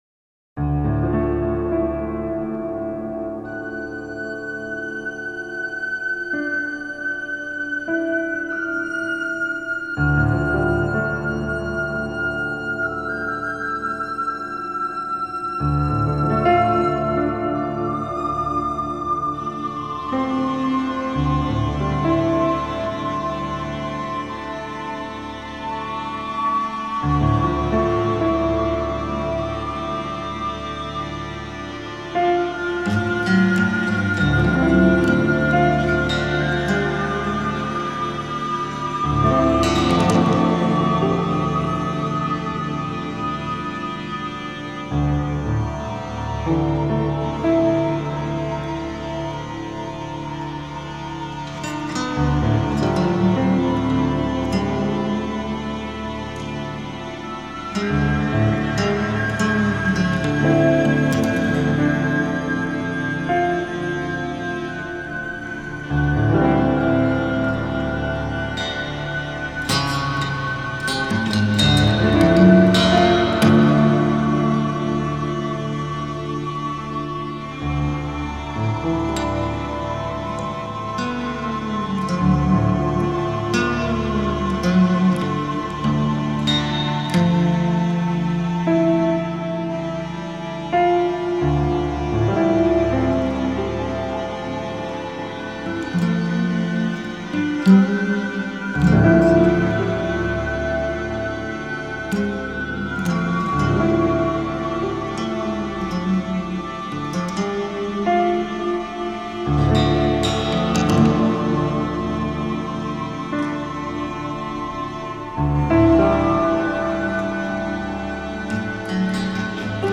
Там сосульками звенит... ты чтоле Снежная Королева? )))